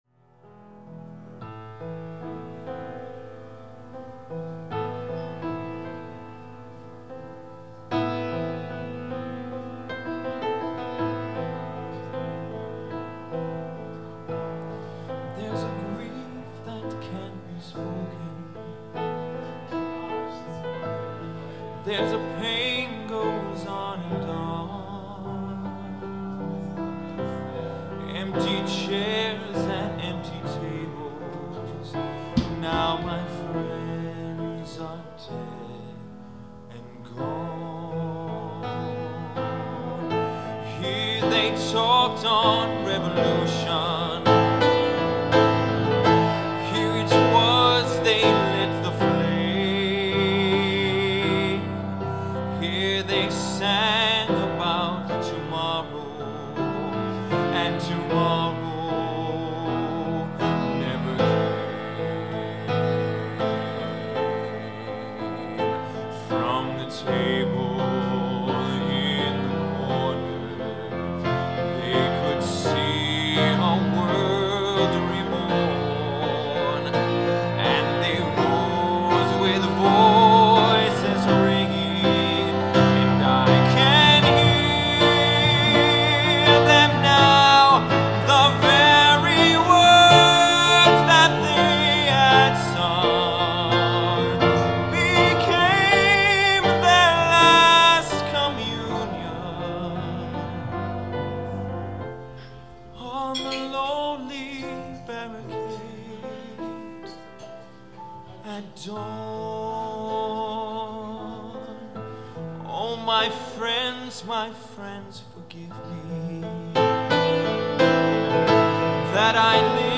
live at Gianna's, NYC